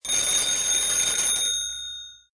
telephone_ring.ogg